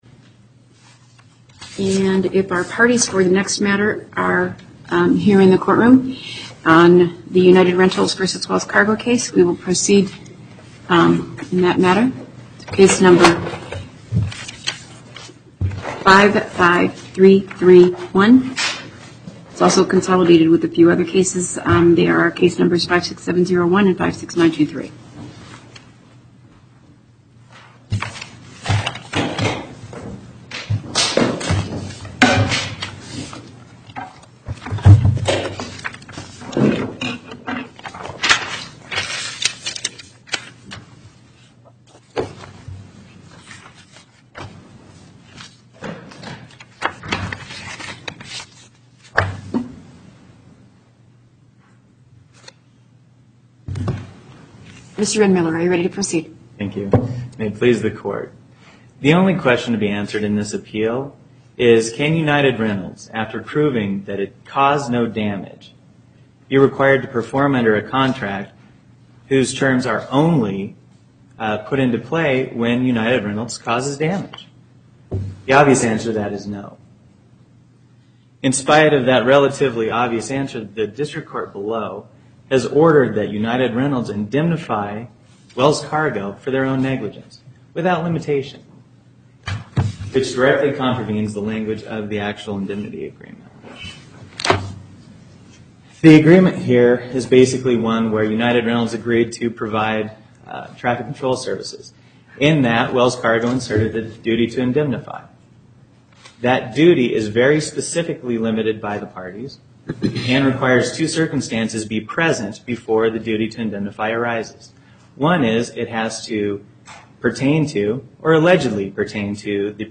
Location: Carson City Before the Northern Nevada Panel, Justice Saitta Presiding